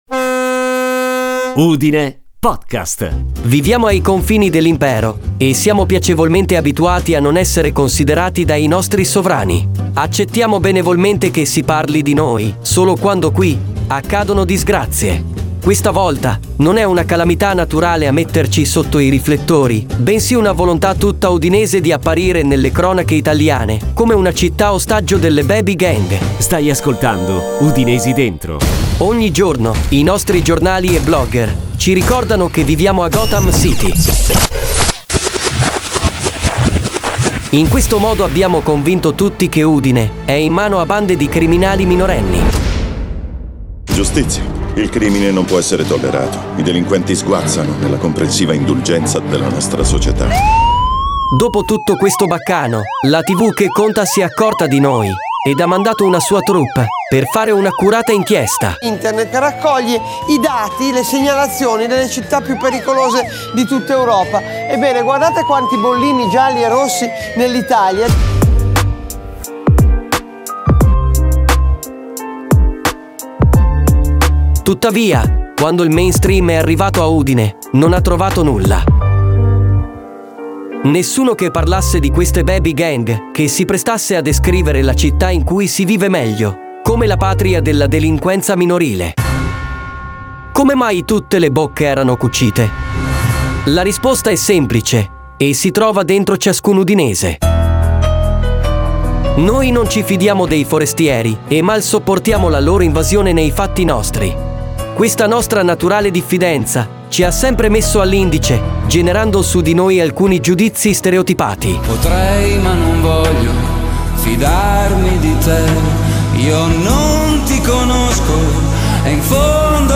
la voce sintetica di Vittorio